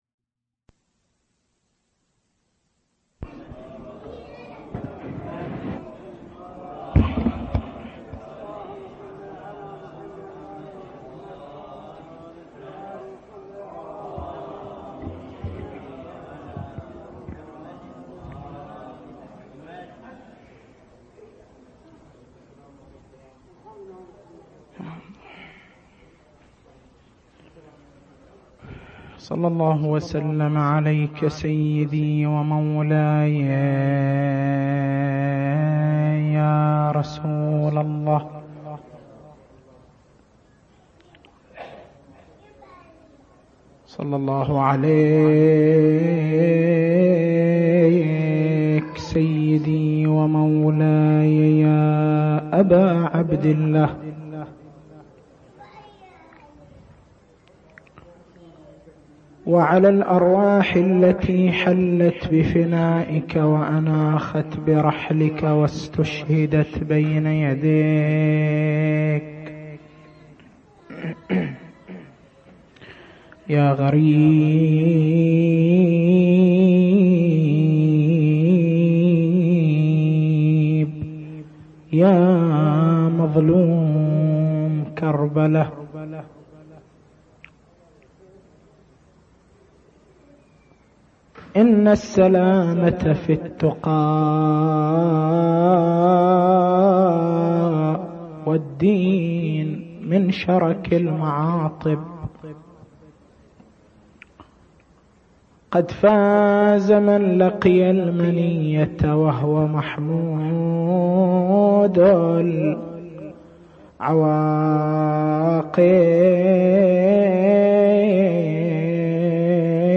مكتبة المحاضرات